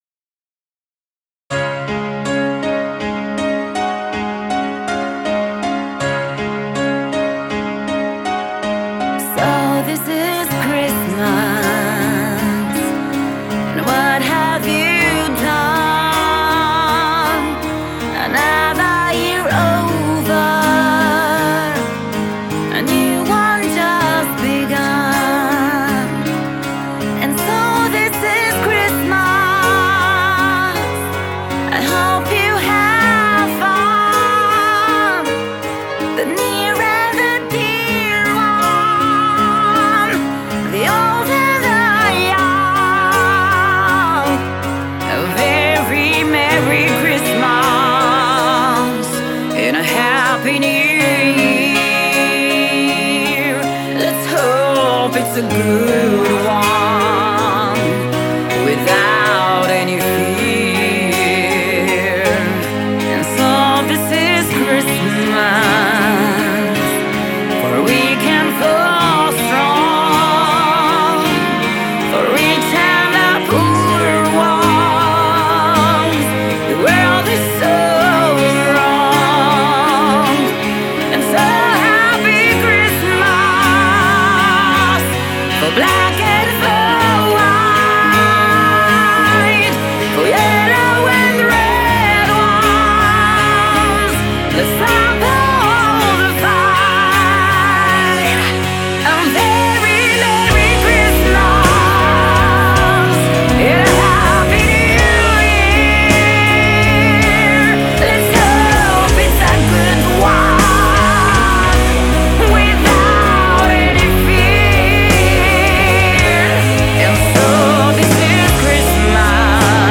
heavy metal cu voce feminină
Lead Vocals
Lead guitar & Vocals
Bass guitar & Vocals
Drums
Keyboards